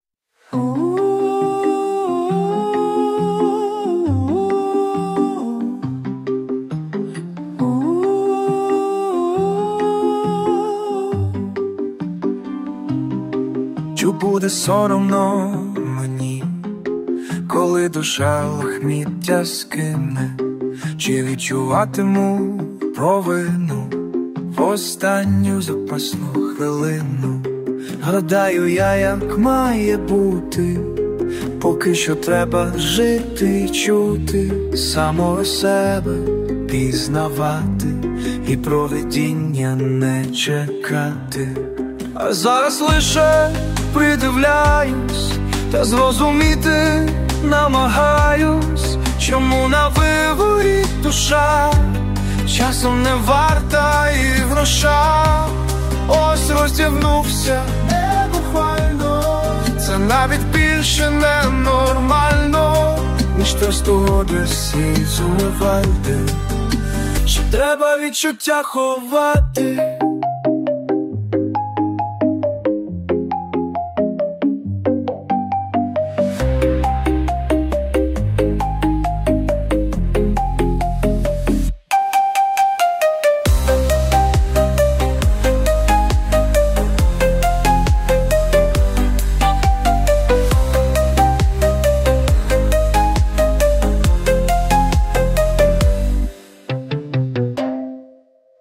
Виконання пісні - ШІ.
ТИП: Пісня
СТИЛЬОВІ ЖАНРИ: Філософський